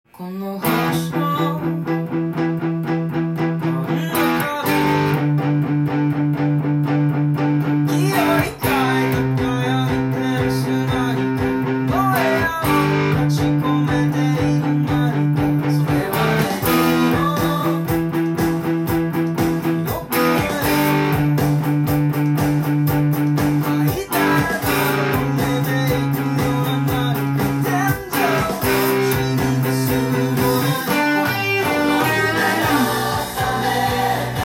音源に合わせて譜面通り弾いてみました
殆どパワーコードと8分音符で構成されていますので
ブリッジミュートをして低音を強調するとロックなギターが弾けます。